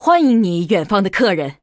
文件 文件历史 文件用途 全域文件用途 Lobato_tk_03.ogg （Ogg Vorbis声音文件，长度1.6秒，111 kbps，文件大小：22 KB） 文件说明 源地址:游戏语音 文件历史 点击某个日期/时间查看对应时刻的文件。 日期/时间 缩略图 大小 用户 备注 当前 2018年11月17日 (六) 03:35 1.6秒 （22 KB） 地下城与勇士  （ 留言 | 贡献 ） 分类:洛巴赫 分类:地下城与勇士 源地址:游戏语音 您不可以覆盖此文件。